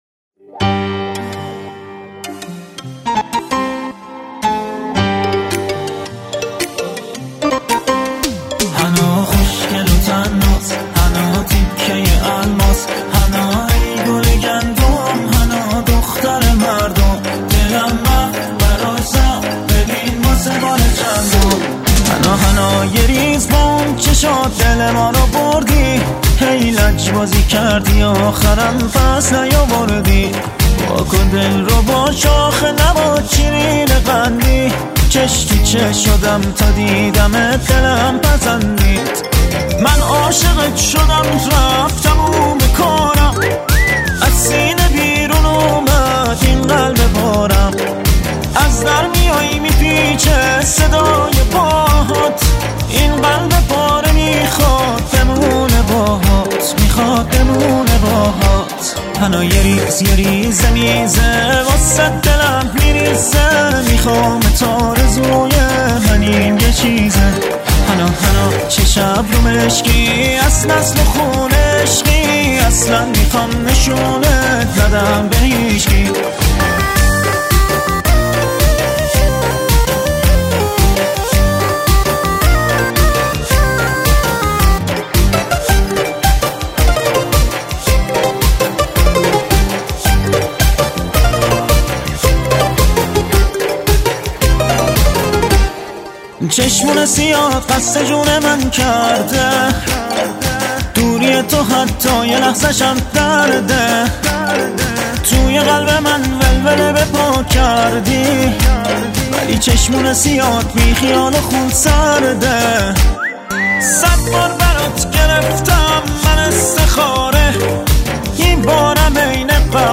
عاشقانه و احساسی